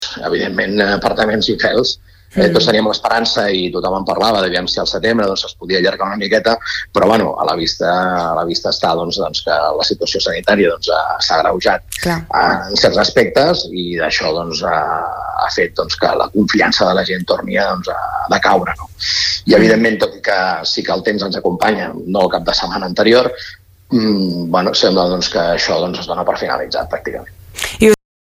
En una entrevista al Supermatí de Ràdio Capital